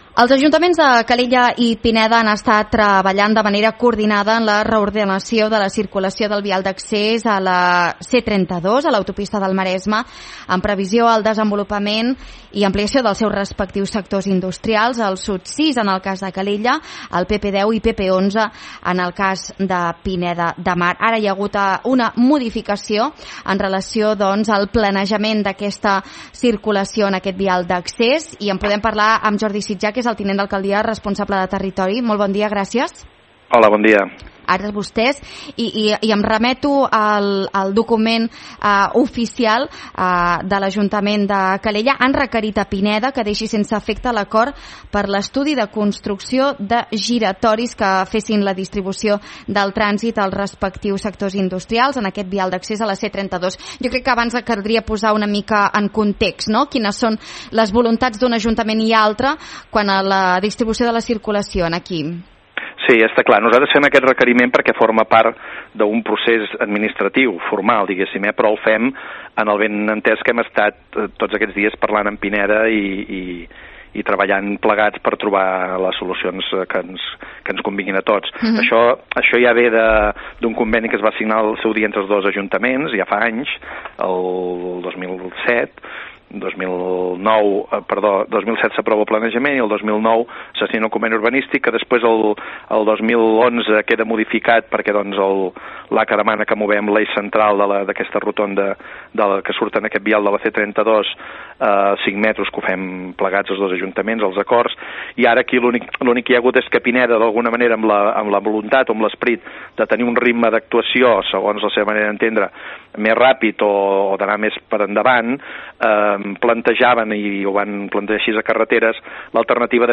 A continuació podeu recuperar íntegrament l’entrevista al tinent d’Alcaldia de Territori, Jordi Sitjà.
2523-ENTREVISTA-ROTONDES-INDUSTRIALS-JORDI-SITJÀ.mp3